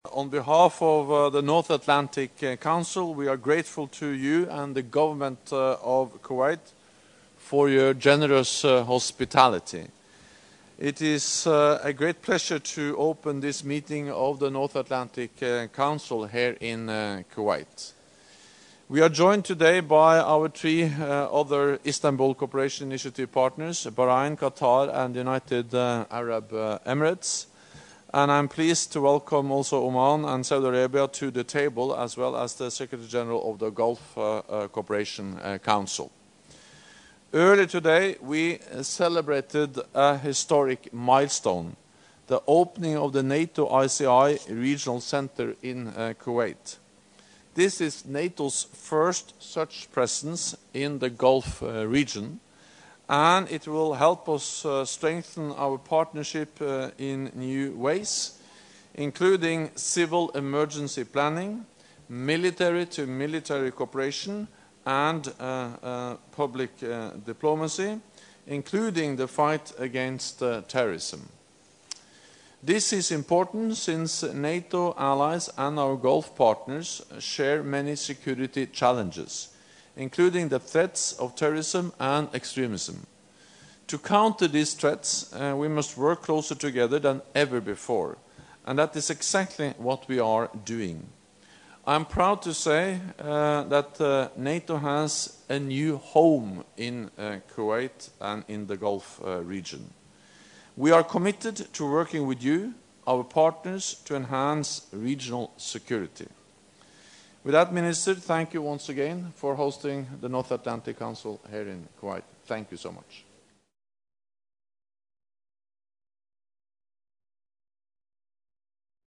Opening remarks by NATO Secretary General Jens Stoltenberg at the meeting of the North Atlantic Council with ICI Partners and participation of Oman, Saudi Arabia, and the Gulf Cooperation Council
(As delivered)